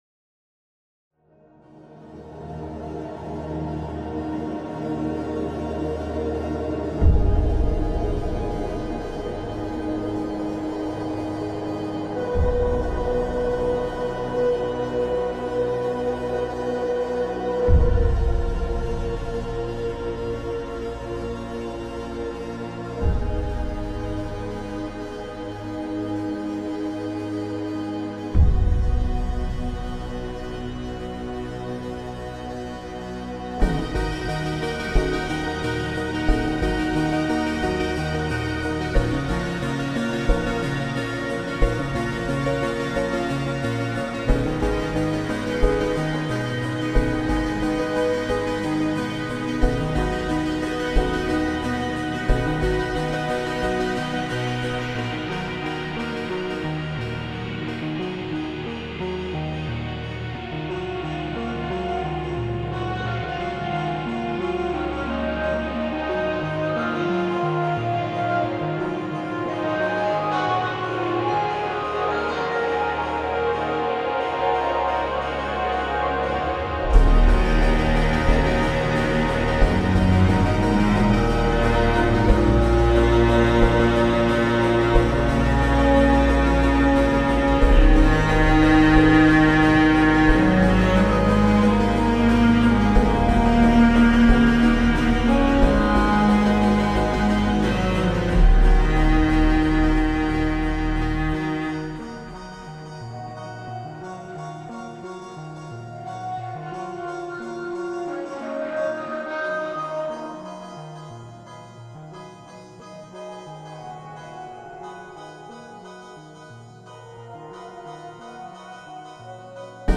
موزیک متن دلنشین و متناسب بازی
رگه‌هایی از پست راک دارد